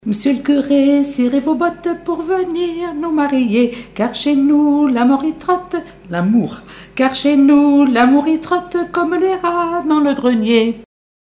Chants brefs - A danser
danse : branle : avant-deux
Témoignages et chansons
Pièce musicale inédite